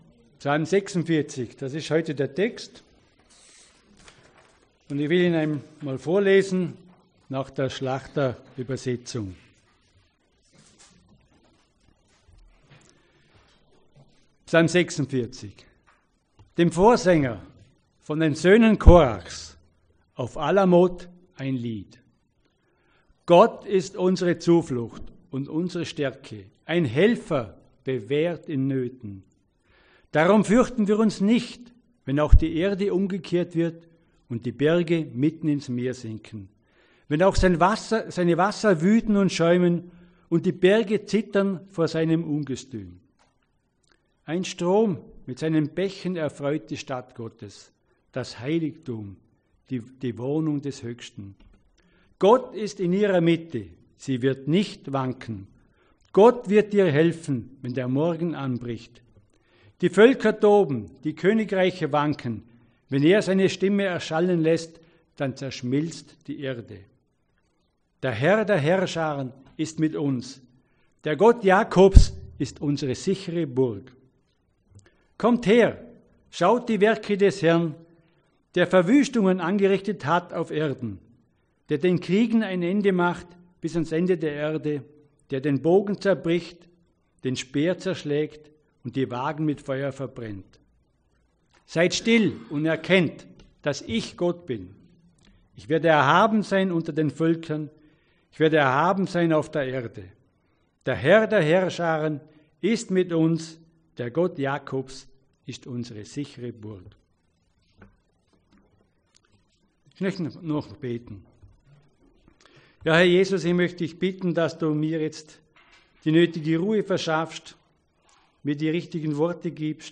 PREDIGTEN – Seite 35 – CGD Dornbirn